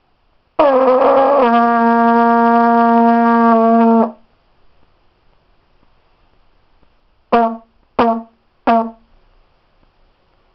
Jeg lavede en lydoptagelse af en kamelprut. Det var en ordentlig en, og jeg kan huske at jeg troede på det tidspunkt at dette måtte være den højeste prut i Indien.
Kamelprut.wav